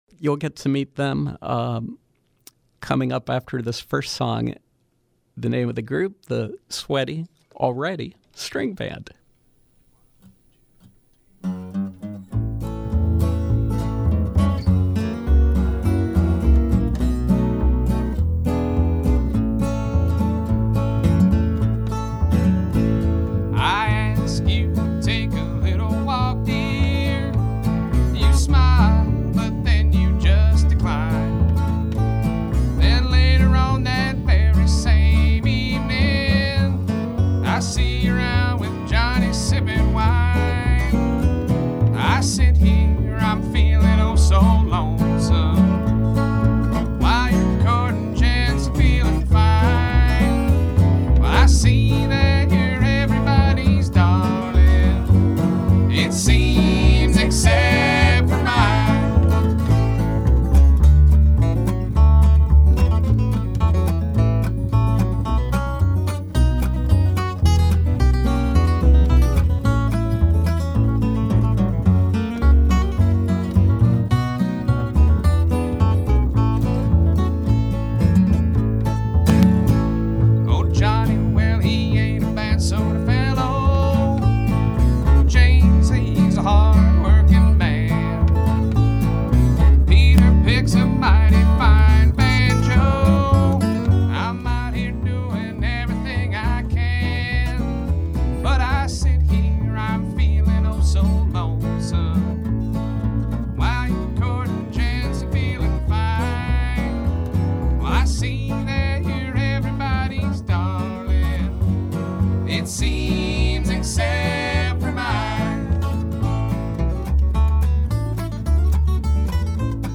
Bluegrass